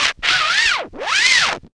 WIND UP.wav